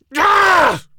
B_pain5.ogg